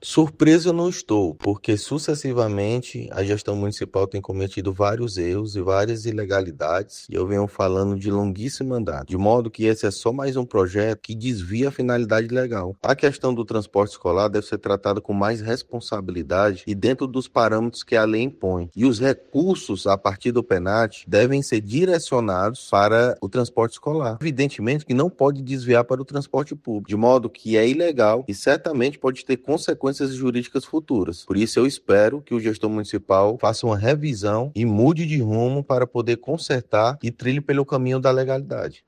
Confira abaixo, a fala do vereador na íntegra: